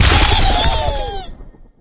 flea_egg_explo_01.ogg